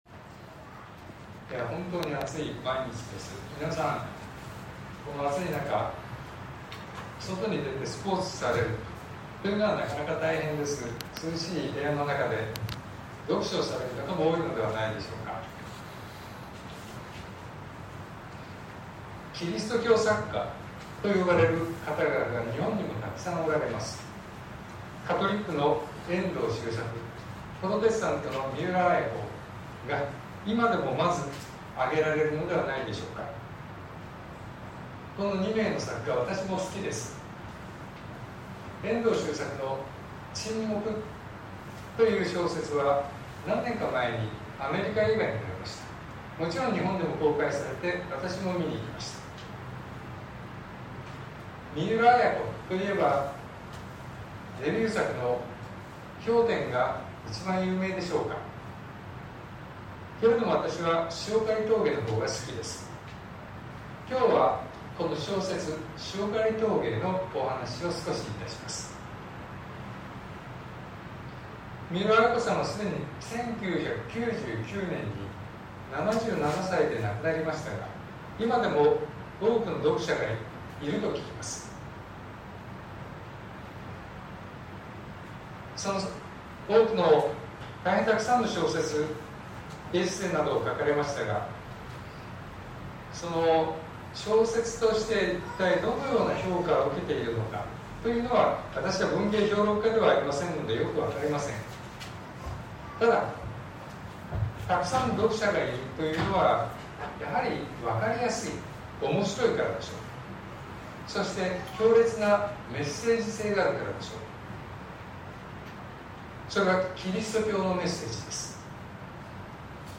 2023年08月20日朝の礼拝「三浦綾子『塩狩峠』を読む」東京教会
説教アーカイブ。